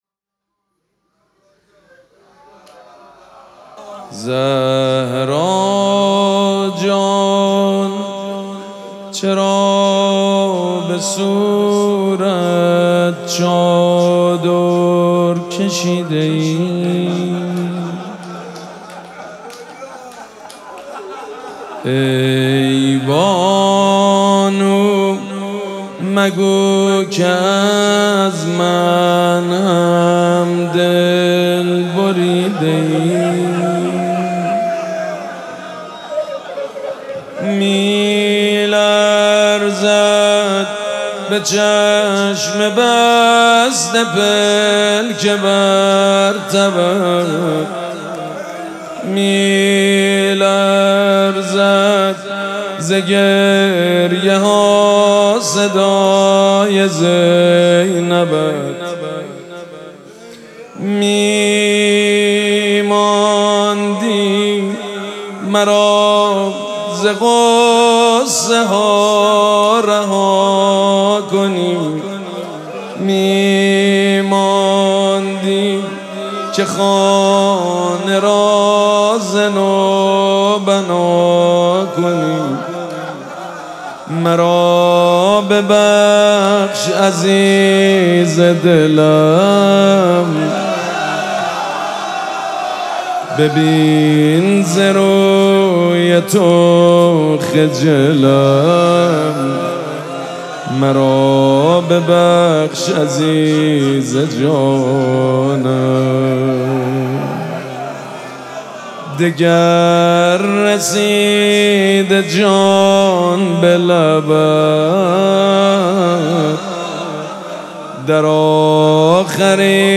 شب چهارم مراسم عزاداری دهه دوم فاطمیه ۱۴۴۶
حسینیه ریحانه الحسین سلام الله علیها
روضه
حاج سید مجید بنی فاطمه